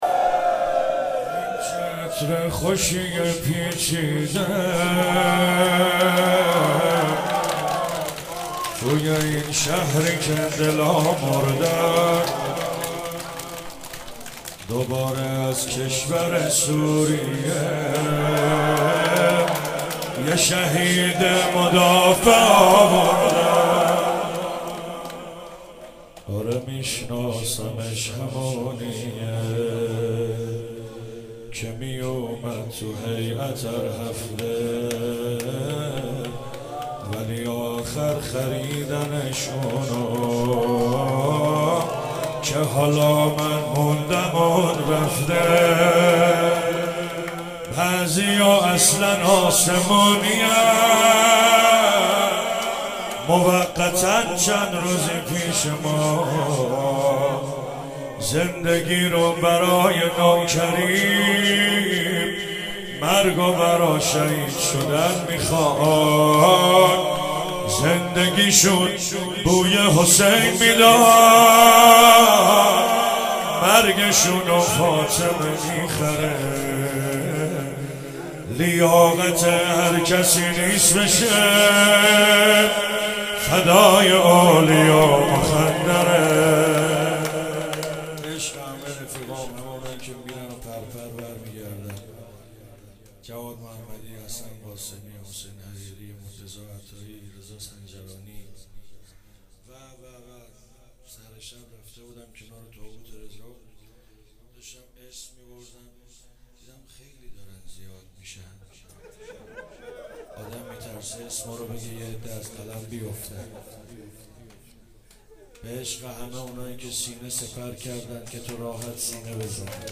شب هفتم محرم 96 - شور - این چه عطر خوشیه پیچیده